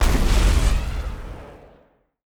weapon_flame_003.wav